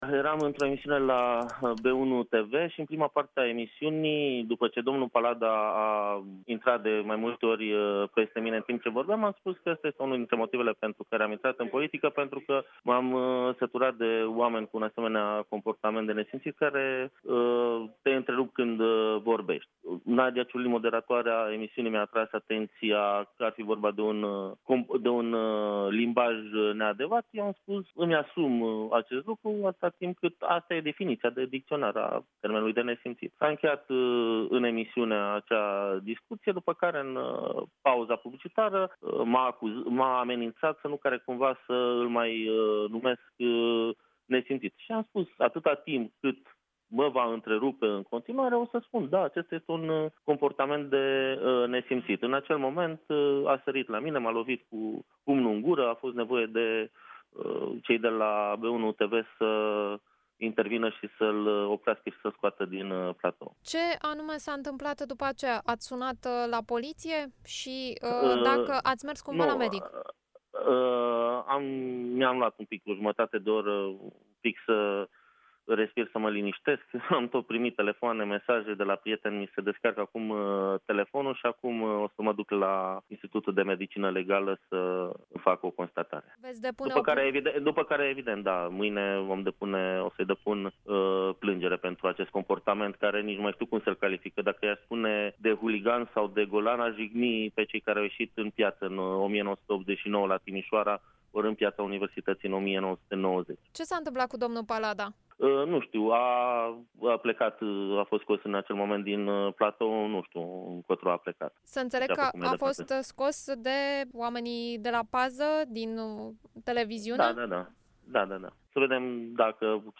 La Europa FM, Mihai Goțiu  spune că  a avut un schimb dur de replici cu fostul purtător de cuvânt al Guvernului, în prima parte a emisiunii de la B1 iar incidentul a avut loc în pauza publicitară.